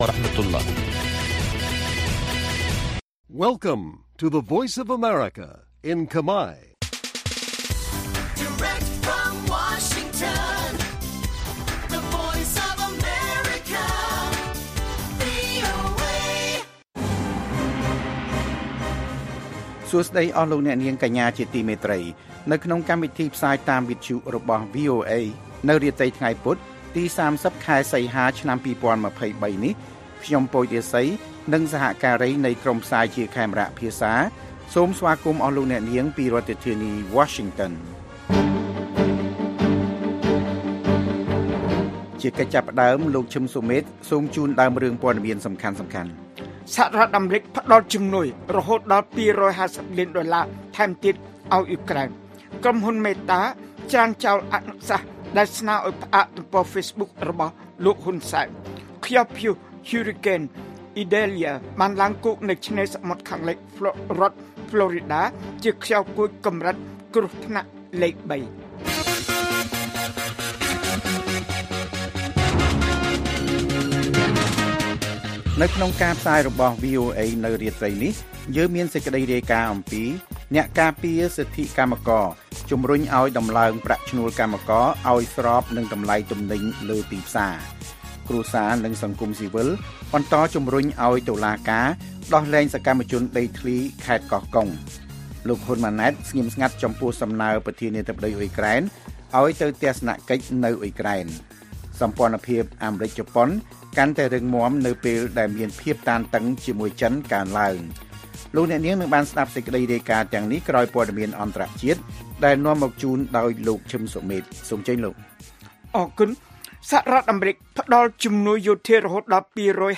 ព័ត៌មានពេលយប់ ៣០ សីហា៖ អ្នកការពារសិទ្ធិកម្មករជំរុញឱ្យដំឡើងប្រាក់ឈ្នួលកម្មករឱ្យស្របនឹងតម្លៃទំនិញលើទីផ្សារ